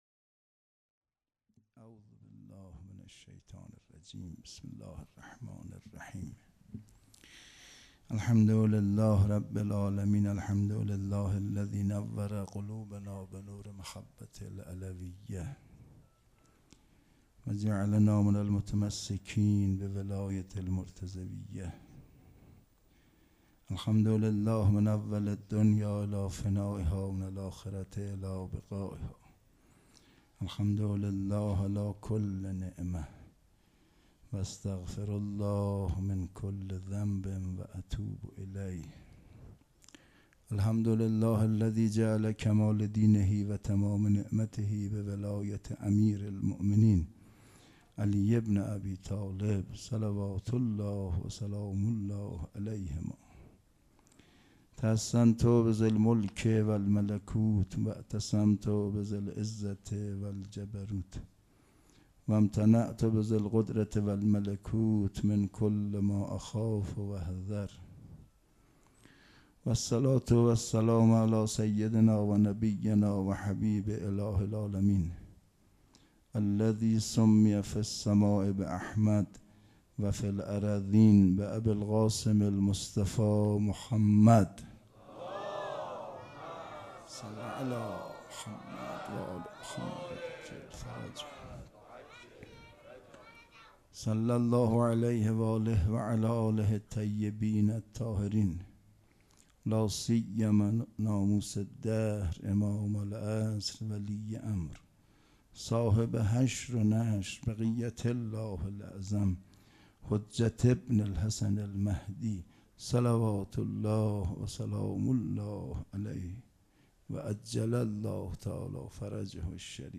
سخنرانی
مراسم عزاداری شام شهادت حضرت زینب(س) پنجشنبه‌ ۲۸ بهمن سال ۱۴۰۰ حسینیه ریحانة‌الحسین (س)